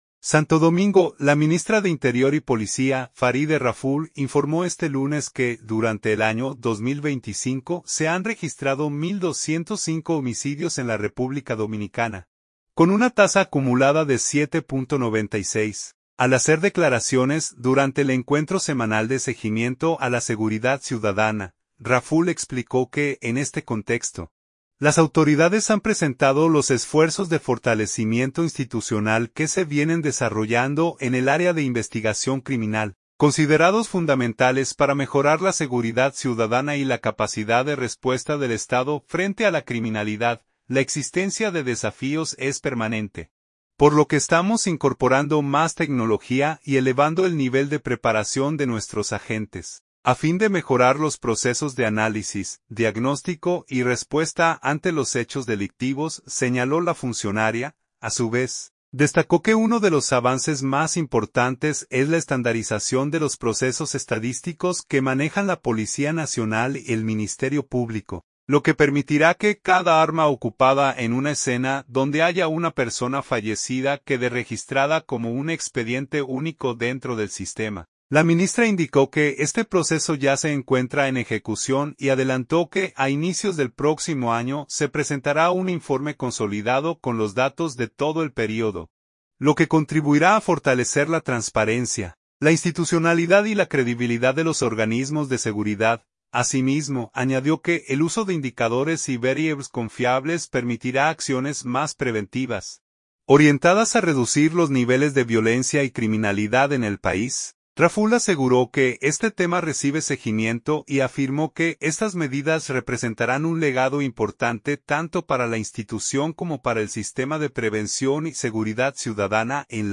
Santo Domingo.– La ministra de Interior y Policía, Faride Raful, informó este lunes que durante el año 2025 se han registrado 1,205 homicidios en la República Dominicana, con una tasa acumulada de 7.96, al ofrecer declaraciones durante el encuentro semanal de seguimiento a la seguridad ciudadana.